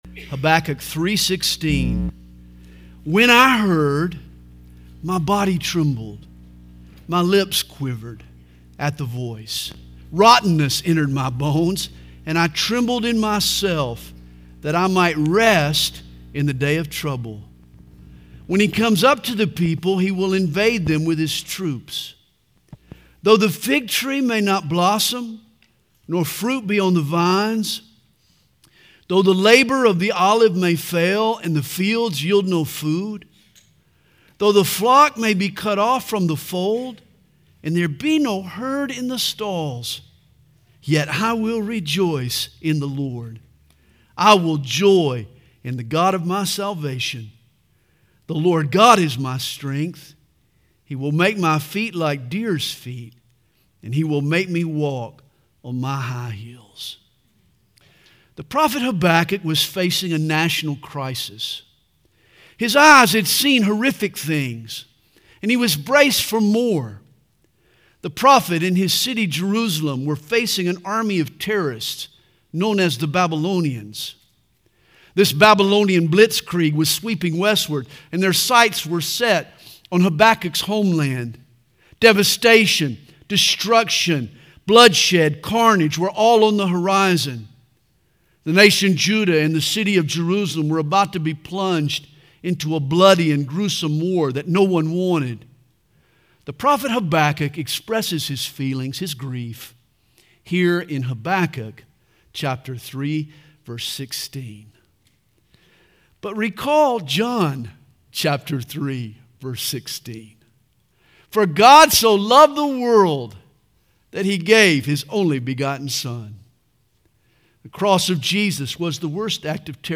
Home » Sermons » Habakkuk 3: In the Day of Trouble